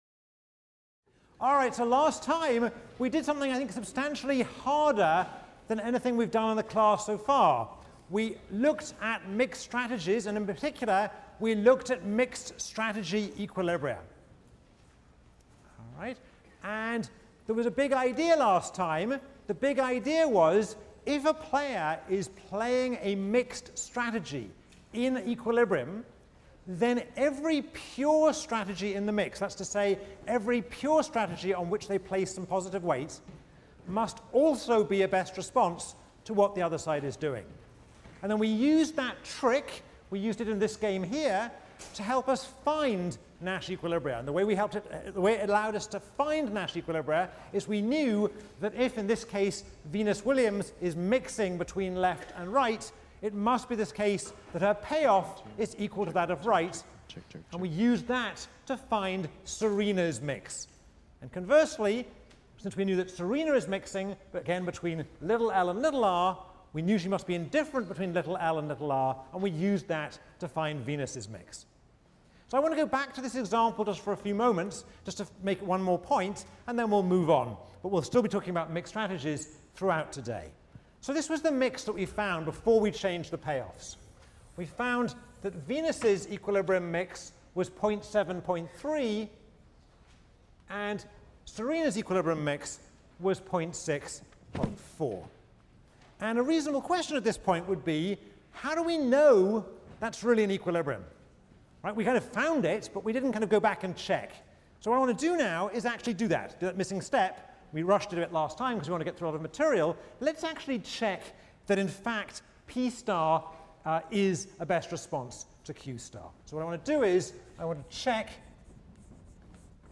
ECON 159 - Lecture 10 - Mixed Strategies in Baseball, Dating and Paying Your Taxes | Open Yale Courses